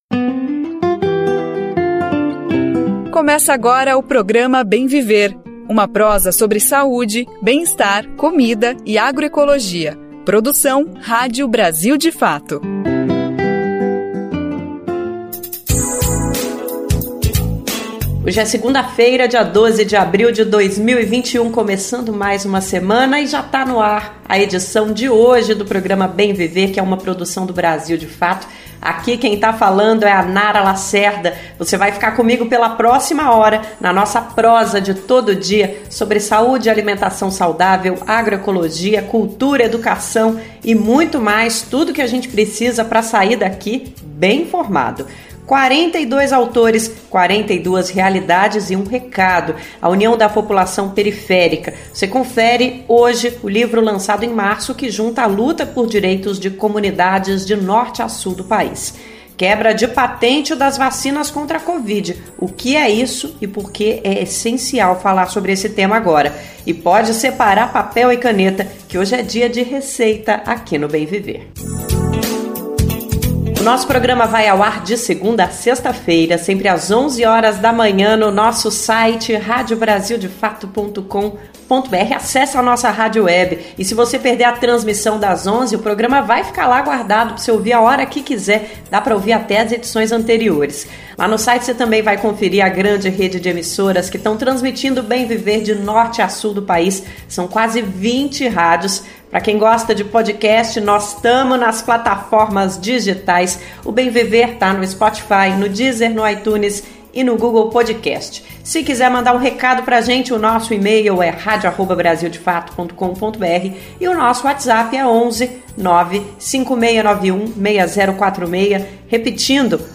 Conversa Bem Viver